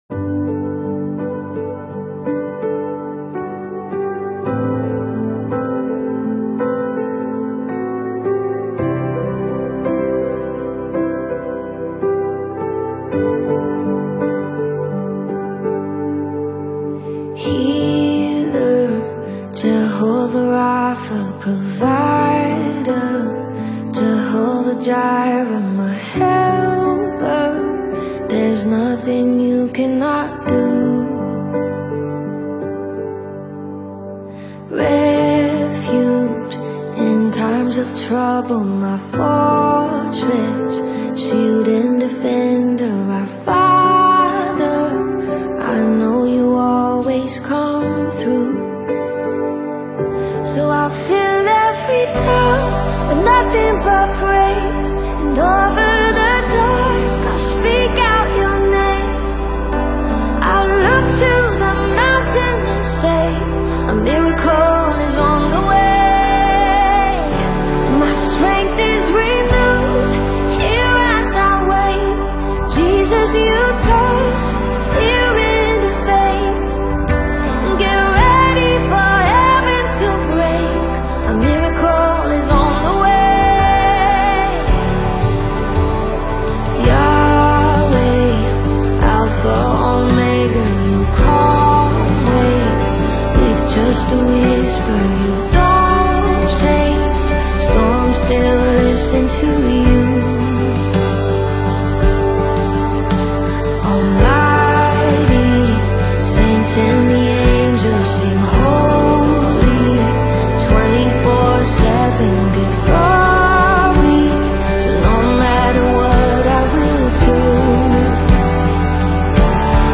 Genre: Gospel/Christian